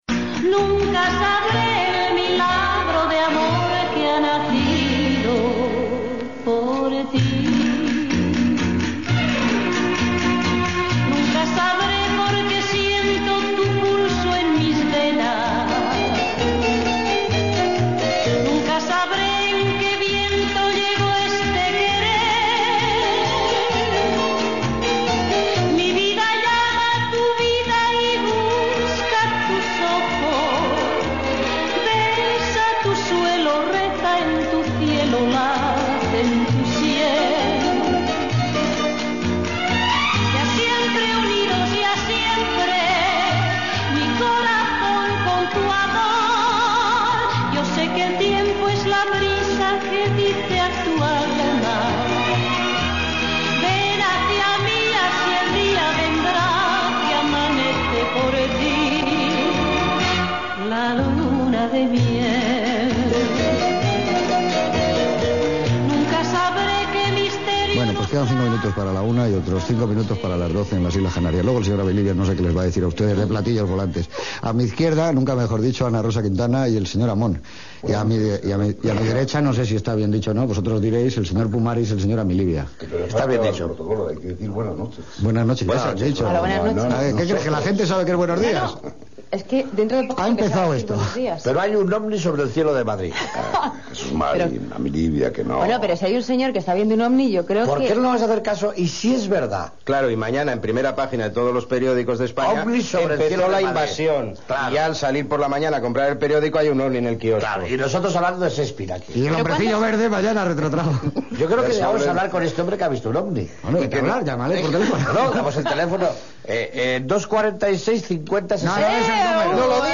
Asignaturas pendientes Gènere radiofònic Entreteniment